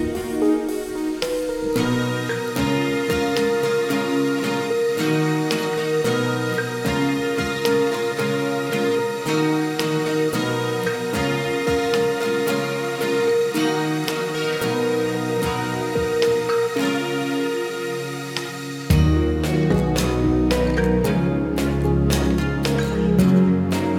Acoustic Version - no Backing Vocals Rock 4:17 Buy £1.50